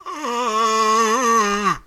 sounds_undead_moan_02.ogg